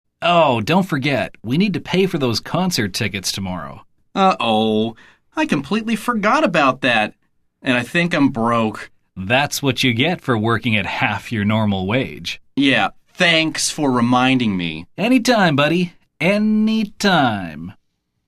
來聽老美怎麼說？
本句重點不在於措辭，而在於說話的方式；說的時候，記得語氣聽起來要很諷刺，一點也沒有感謝的意思。